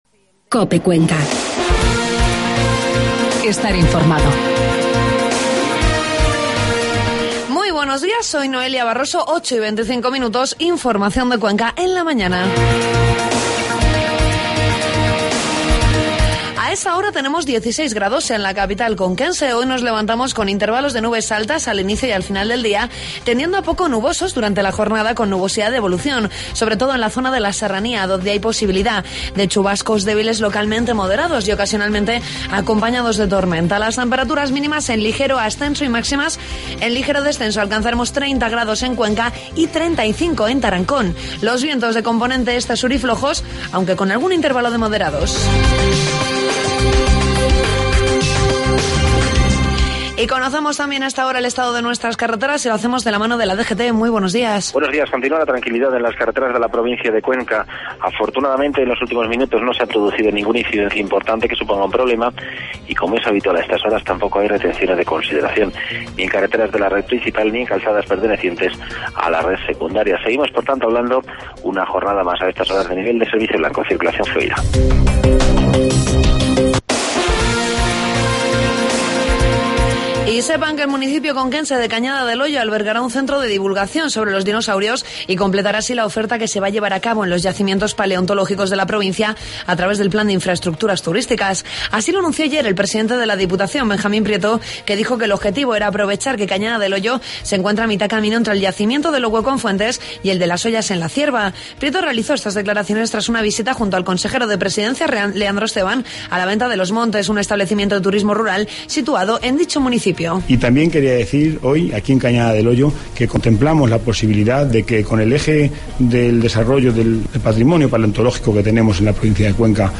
Informativo matinal 2 de julio